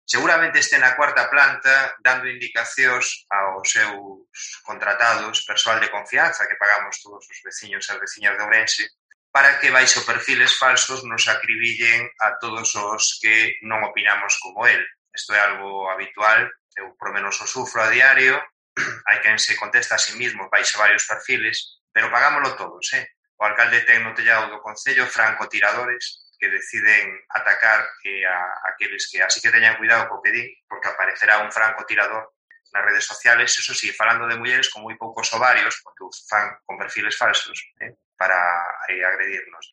Intervención de Pepe Araújo en el Pleno municipal